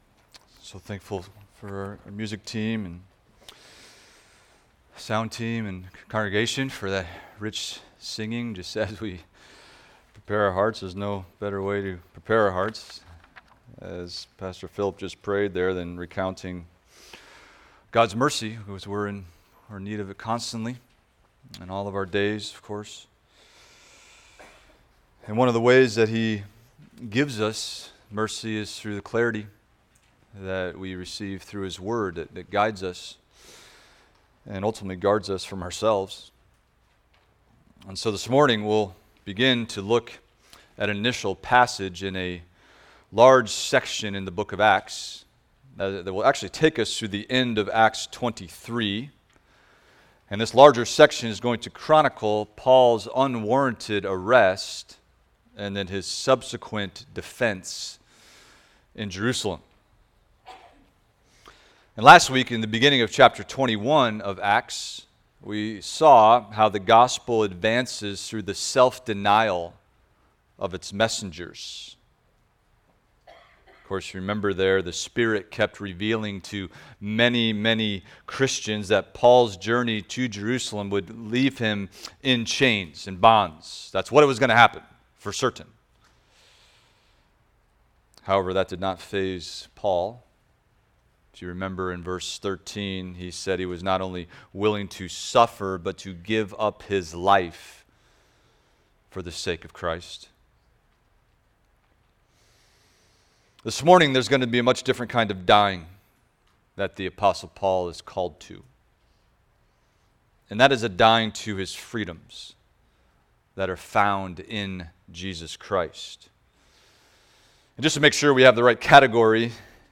Expository Preaching from First Peter – 1 Peter 1:6-9 Rejoicing Greatly in Hope